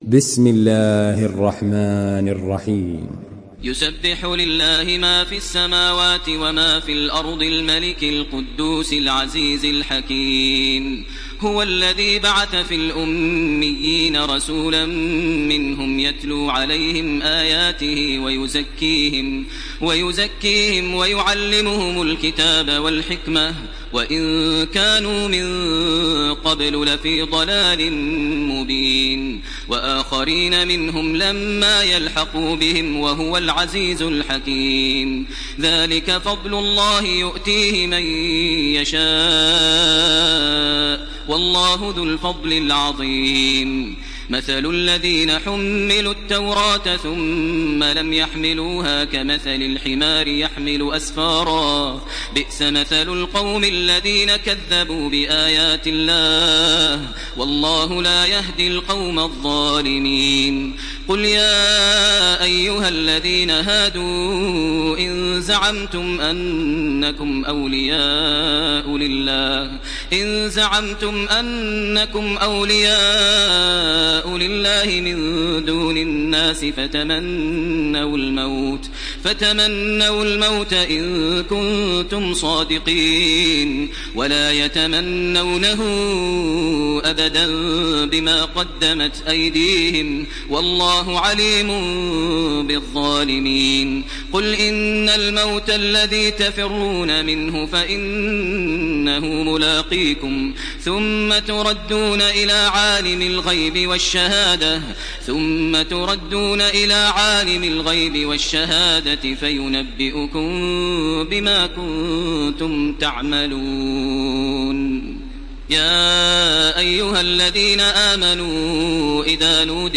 Surah الجمعه MP3 by تراويح الحرم المكي 1429 in حفص عن عاصم narration.
مرتل حفص عن عاصم